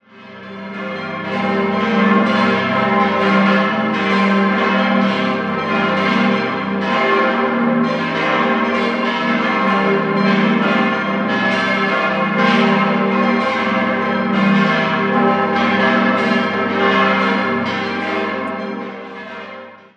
6-stimmiges Geläut: g°-c'-e'-g'-c''-g'' Das Geläut setzt sich aus einer bunten Mischung an Glocken zusammen: (1) Rüetschi 1958, (2) Theodosius Ernst 1655, (3) Jakob Grassmayr 1816, (4) Peter und Johann Melchior Ernst 1730, (5) Peter Ernst 1758, (6) unbekannt 1465.